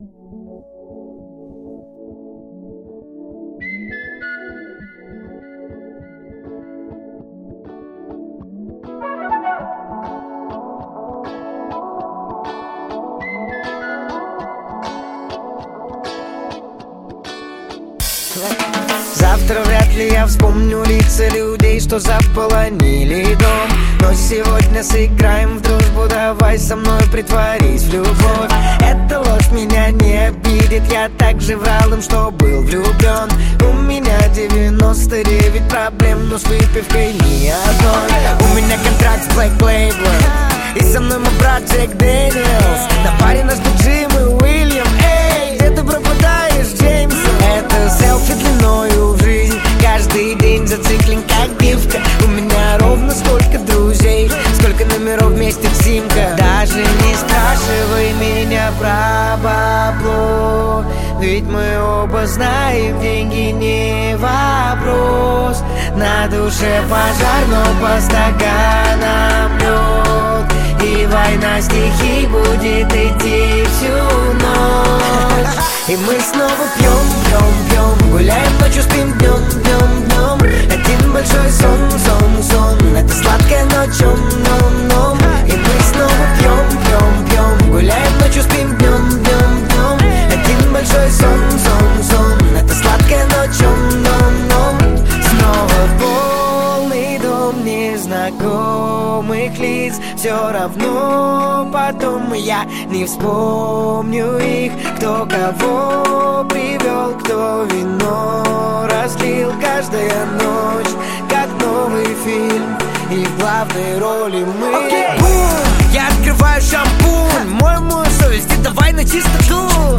Хип-хоп
Жанр: Хип-хоп / Русский рэп